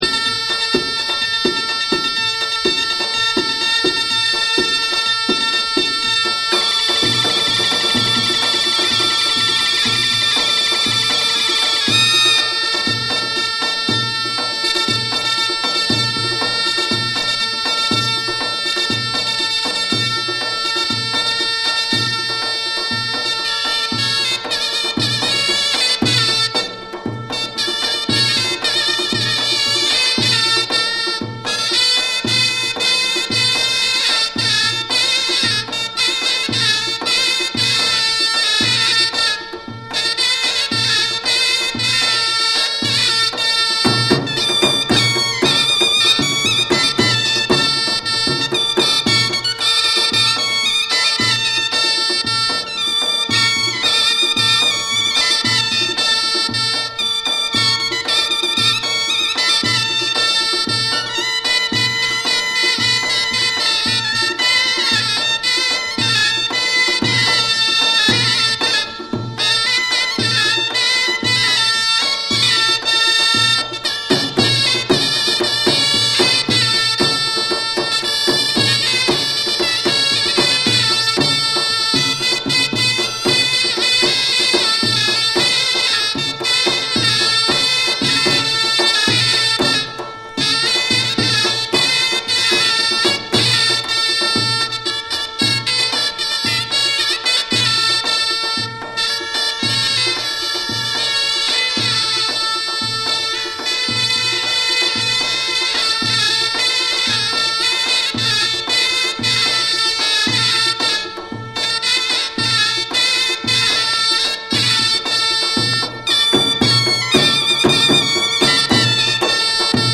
エジプトの伝統音楽を記録。ナイル流域の音楽家たちによる演奏を収めたフィールド録音作品。